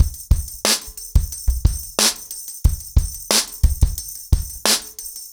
ROOTS-90BPM.13.wav